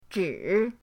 zhi3.mp3